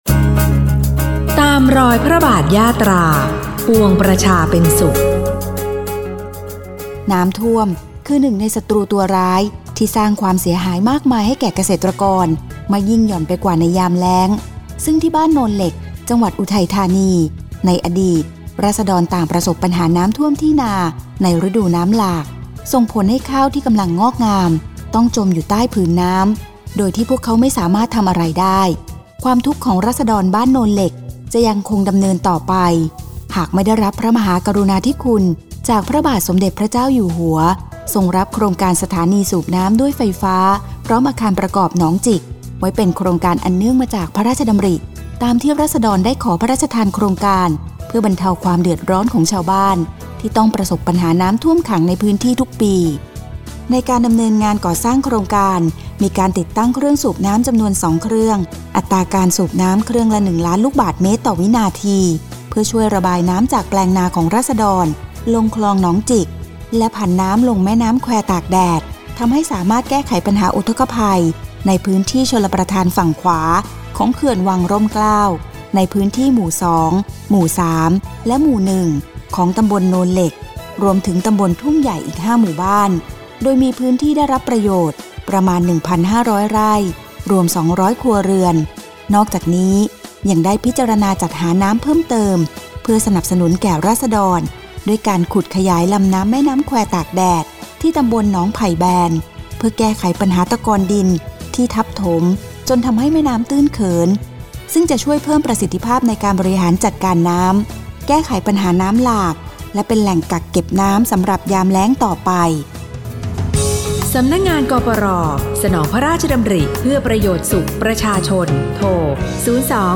ปี 2568 : ข่าวประชาสัมพันธ์ ตอนที่ 1 โครงการสถานีสูบน้ำด้วยไฟฟ้าพร้อมอาคารประกอบหนองจิกฯ